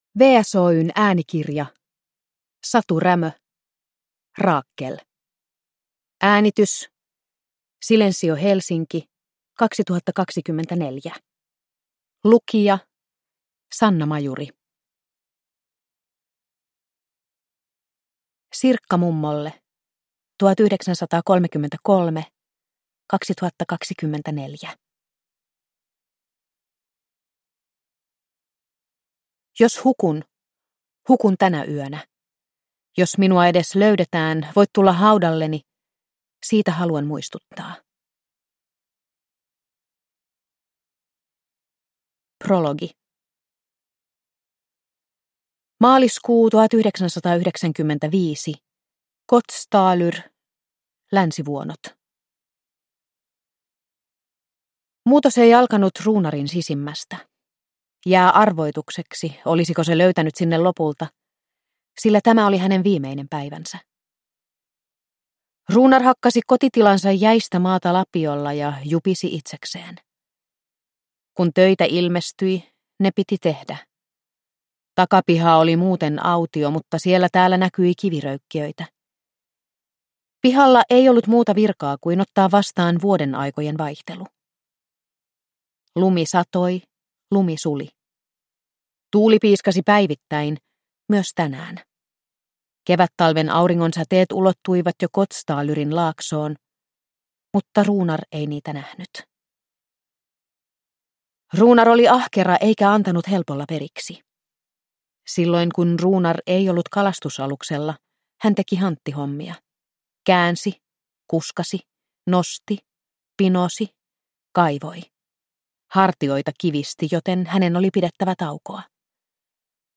Rakel – Ljudbok